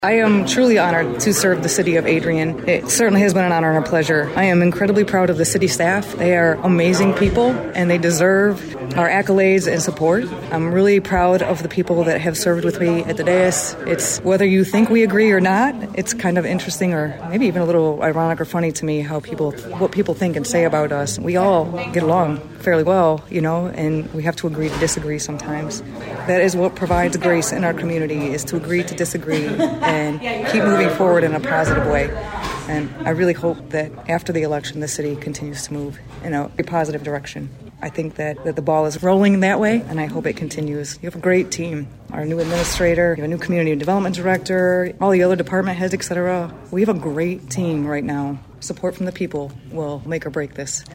That was outgoing Adrian City Commissioner Kelly Castleberry.